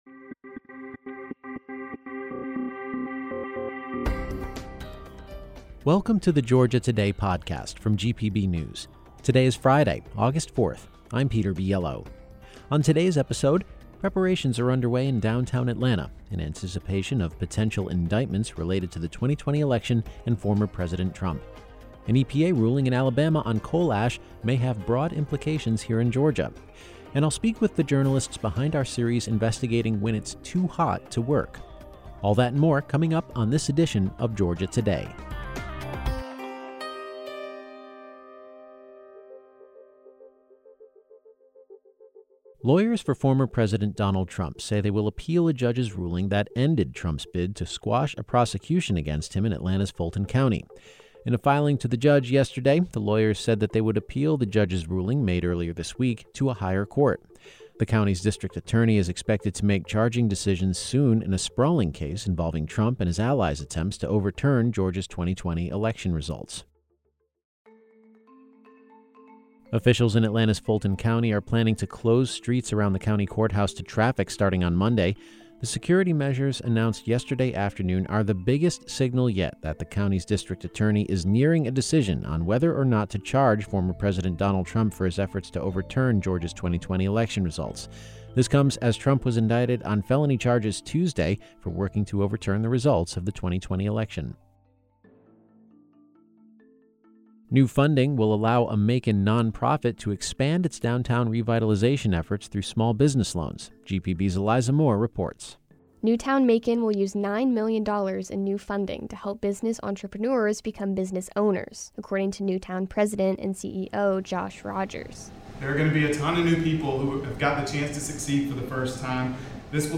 Beschreibung vor 2 Jahren On the Friday, Aug. 4 edition of Georgia Today: Preparations are under way in downtown Atlanta in anticipation of potential indictments related to the 2020 election and former President Trump; an EPA ruling in Alabama on coal ash may have broad implications here in Georgia; and a conversation with the journalists behind our series investigating when it's too hot to work.